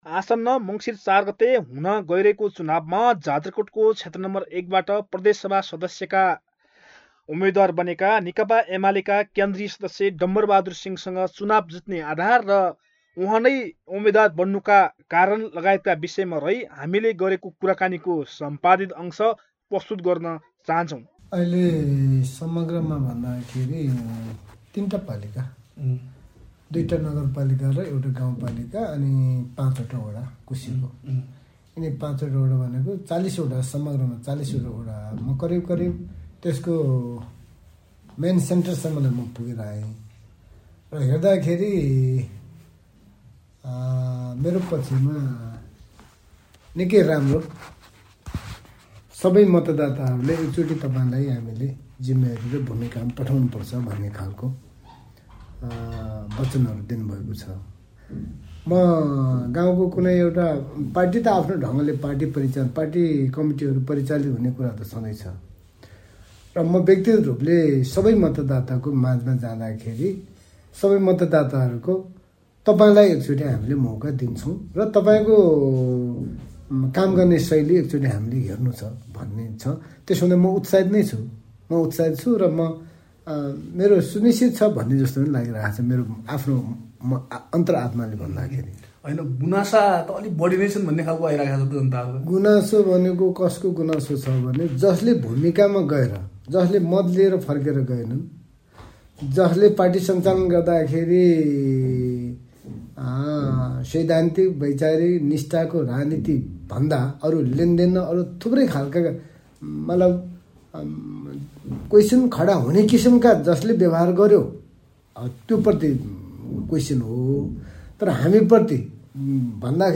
कुराकानीको सम्पादीत अंक